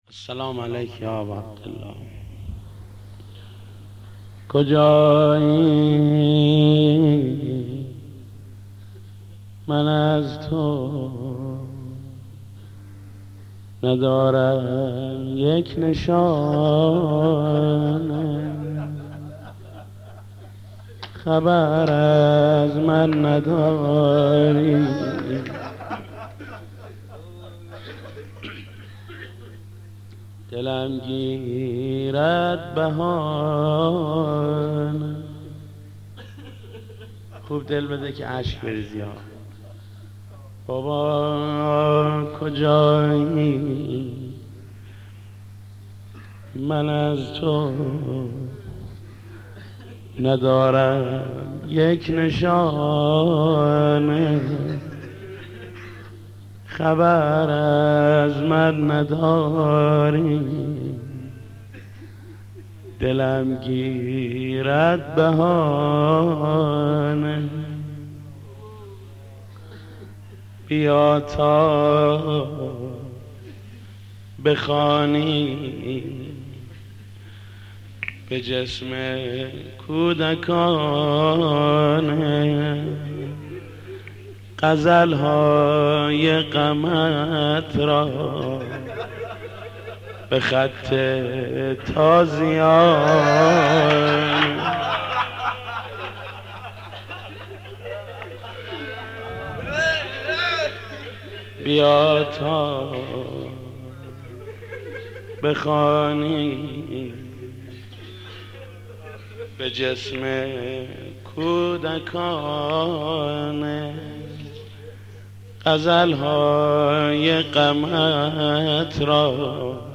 مداح
مناسبت : دهه دوم محرم
مداح : محمود کریمی